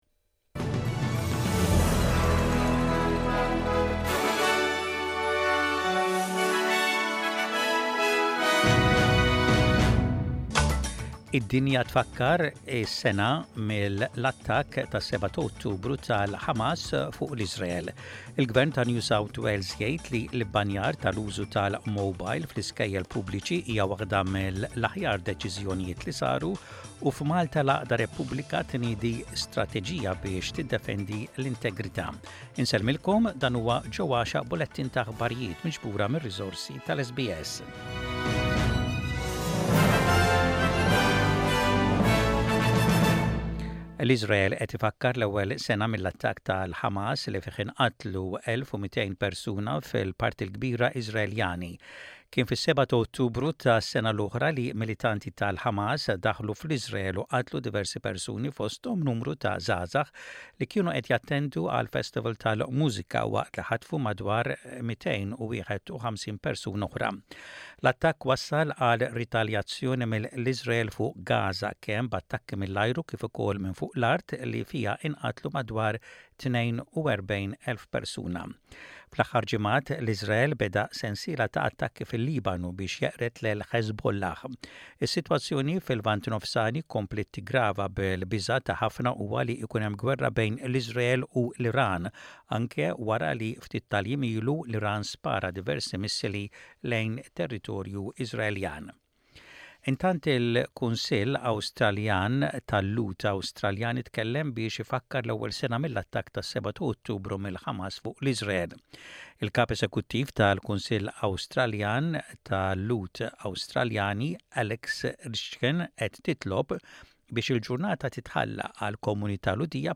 SBS Radio | Aħbarijiet bil-Malti: 08.10.24